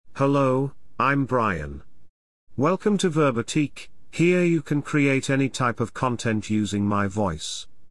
Brian — Male British English AI voice
Brian is a male AI voice for British English.
Voice sample
Listen to Brian's male British English voice.
Brian delivers clear pronunciation with authentic British English intonation, making your content sound professionally produced.